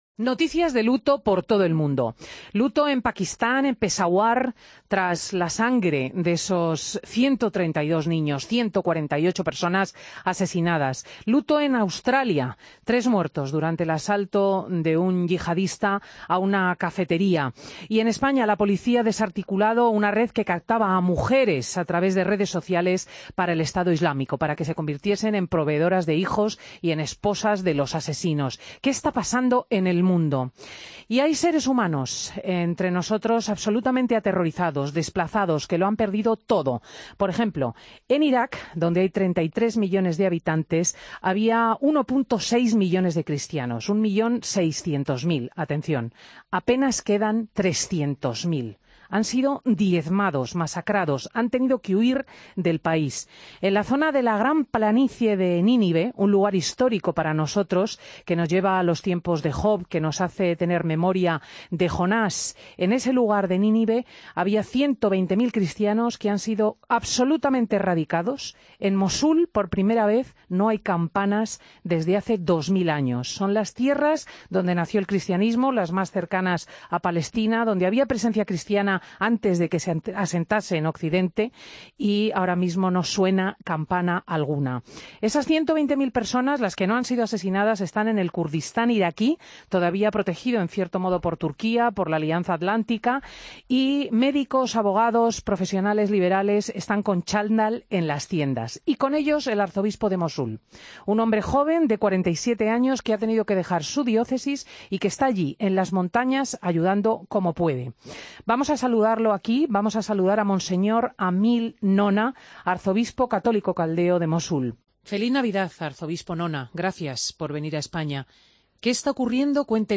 Entrevista a Monseñor Emil Shimoun Nona en Fin de Semana COPE